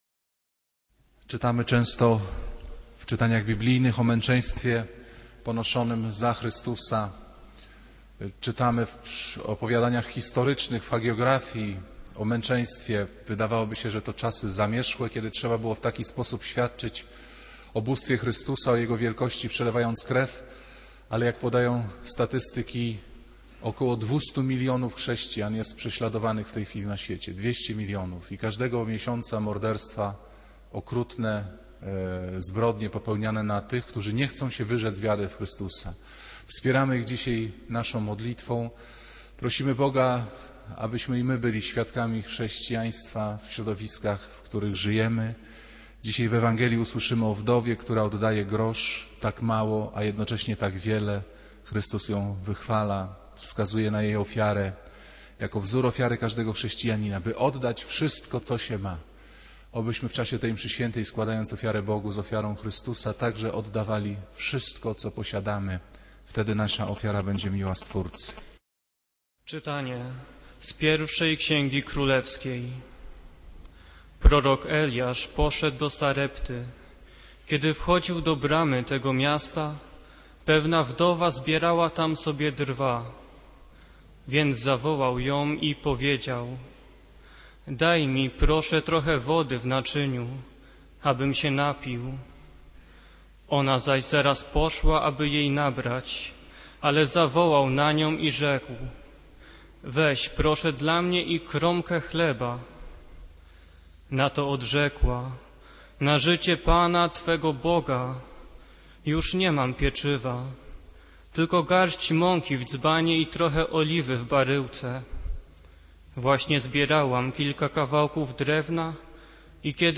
Kazanie z 8 listopada 2009r.